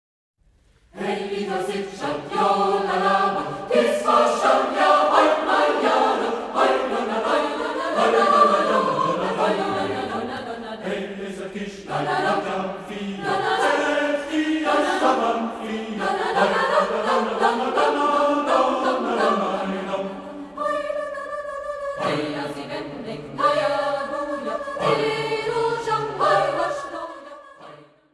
Willkommen beim Folklorechor Plochingen
Folklore international